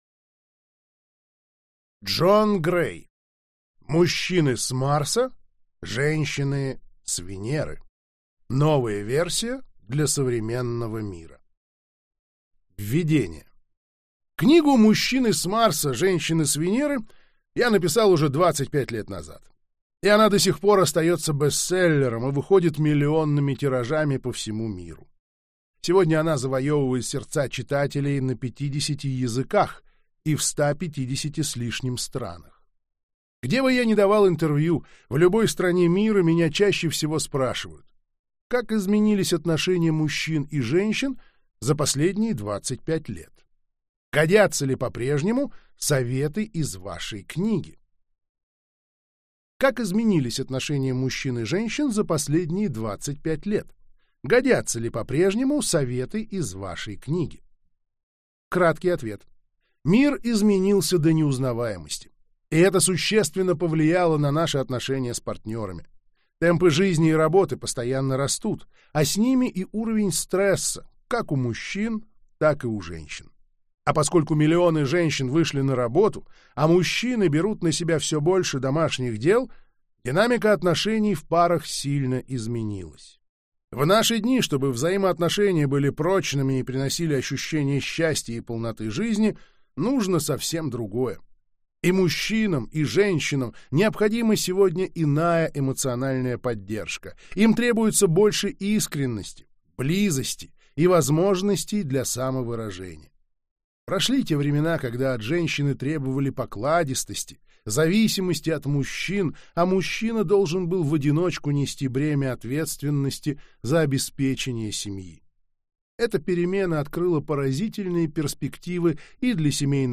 Аудиокнига Мужчины с Марса, женщины с Венеры. Новая версия для современного мира | Библиотека аудиокниг